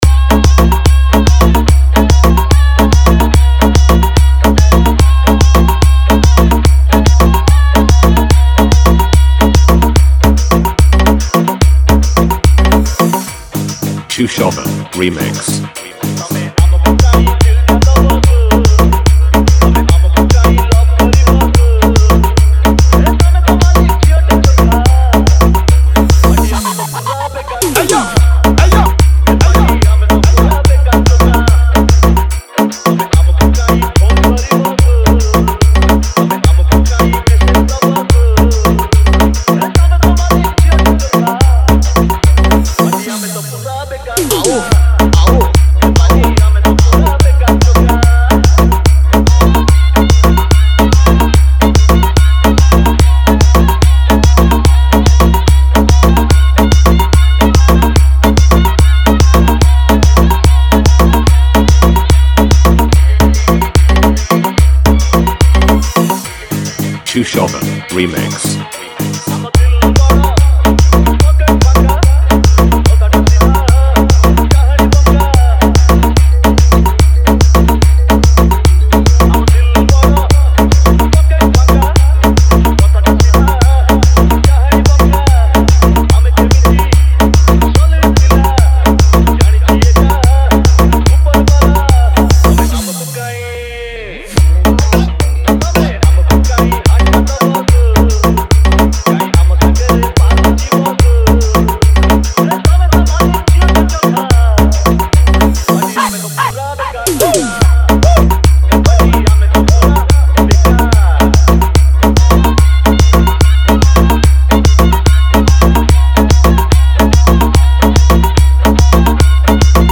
Dj Remixer